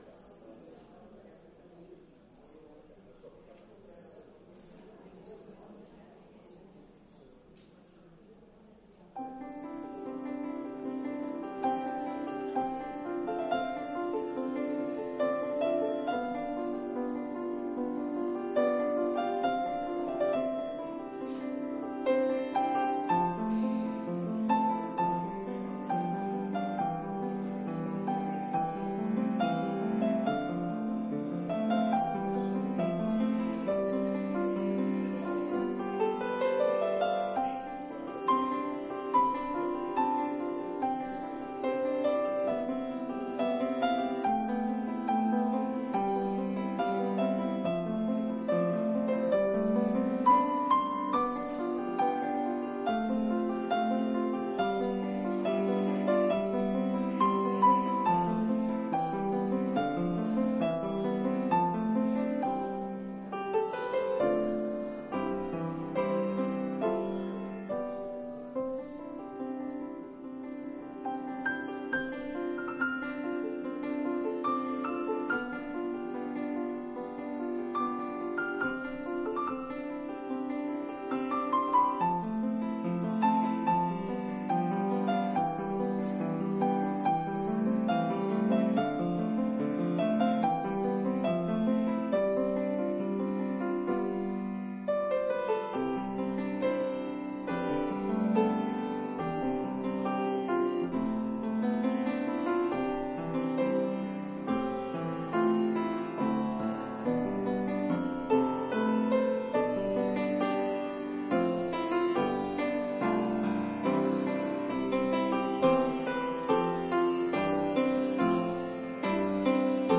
Christmas Eve Service
christmas-eve-service.mp3